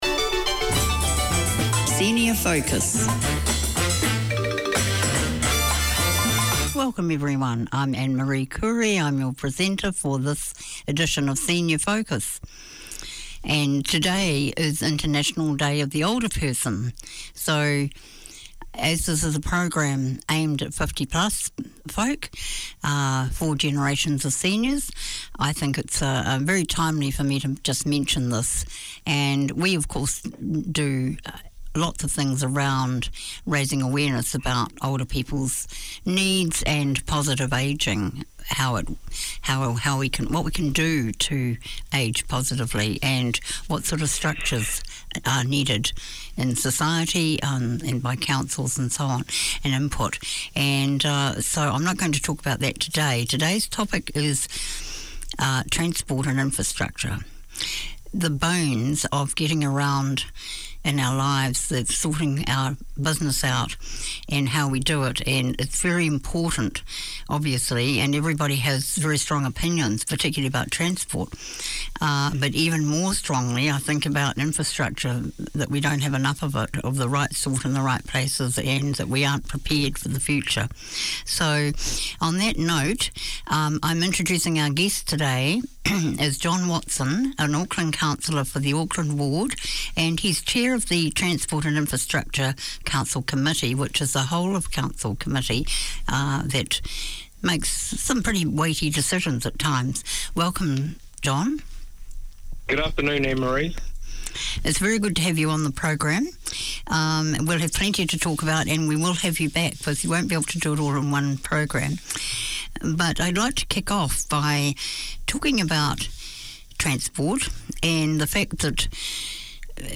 Konkani is the language of Goa, Mangalore, Karwar from South of Maharshtra to north of Kerala and is only spoken, rather than written, so radio is the perfect place to keep the language alive in the Kiwi Konkani community. Your hosts, the Good Guys, play a rich selection of old and contemporary Konkani music, talk with local community personalities, present short radio plays, connect with community events and promote the culture and traditions.